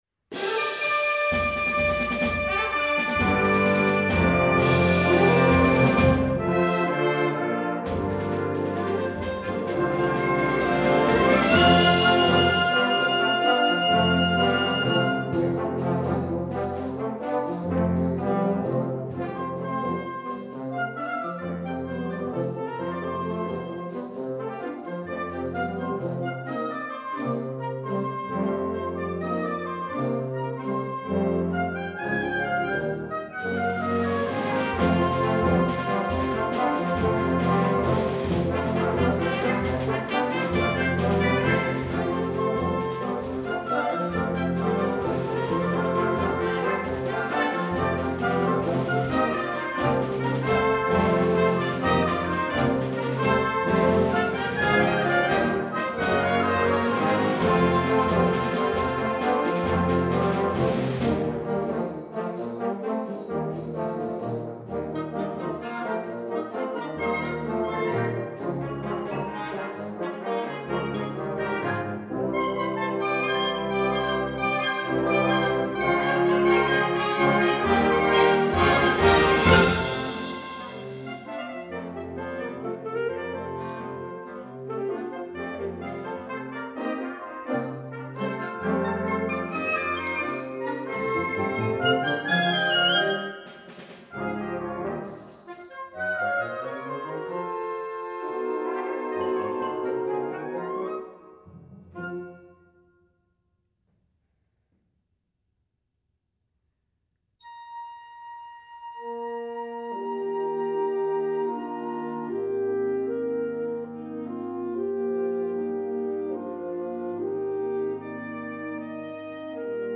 輸入吹奏楽オリジナル作品（スコア＆パート）